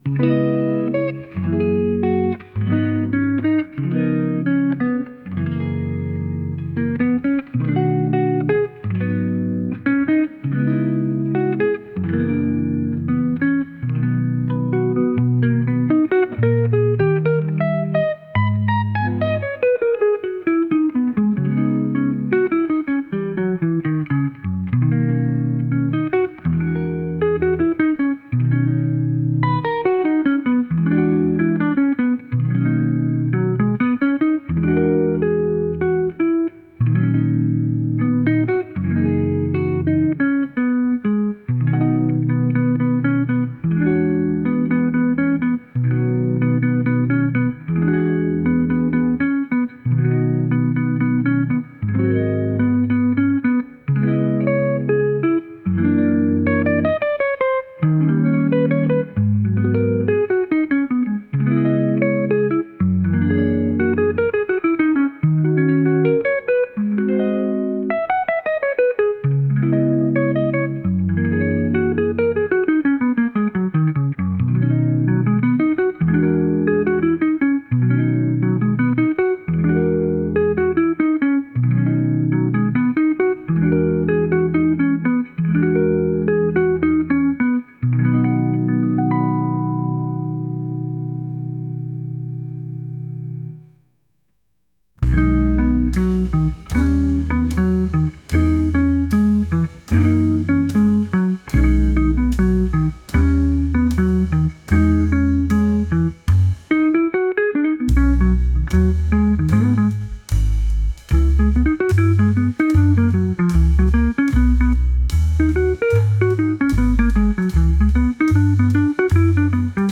ぺそぺそとゆったりとしたエレキギター曲です。